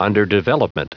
Prononciation du mot underdevelopment en anglais (fichier audio)
Prononciation du mot : underdevelopment